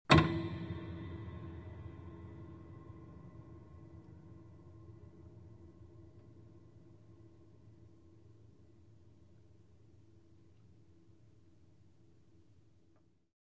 描述：雅马哈三角钢琴踏板释放。
Tag: 混响 钢琴 踏板 释放 维持 钥匙